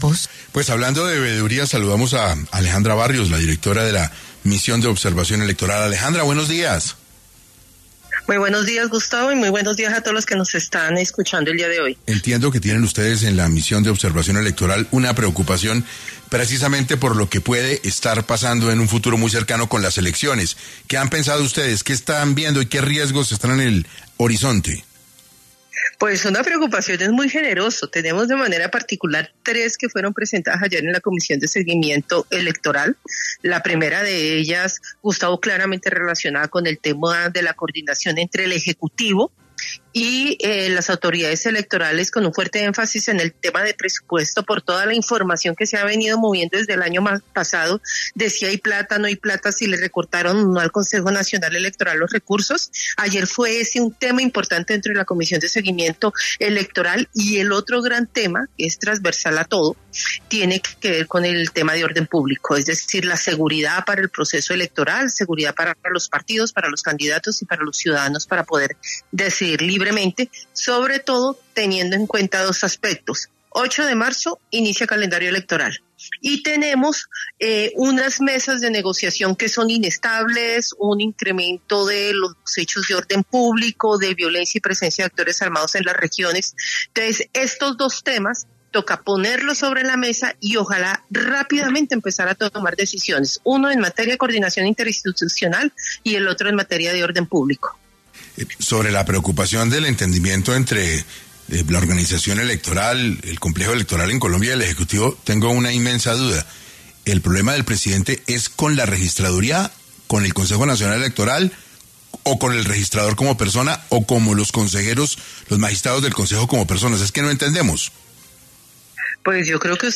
En diálogo con 6AM de Caracol Radio